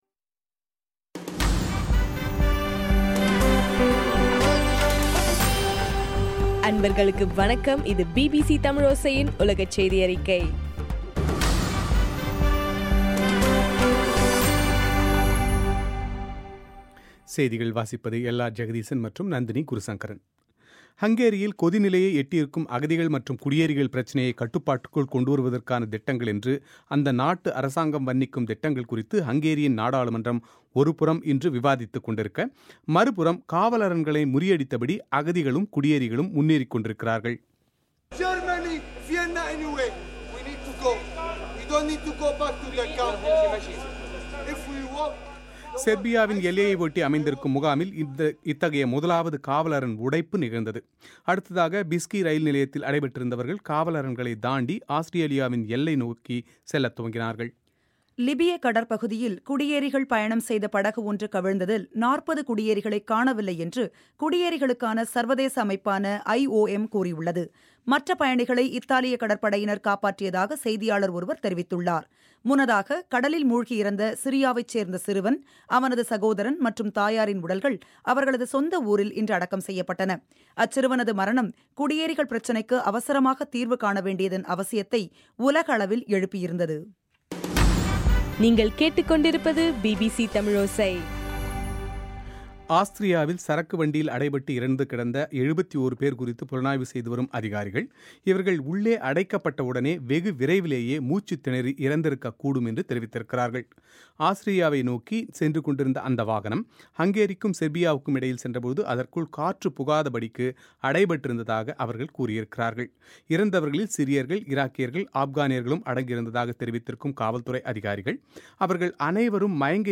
செப்டம்பர் 4, 2015 பிபிசி தமிழோசையின் உலகச் செய்திகள்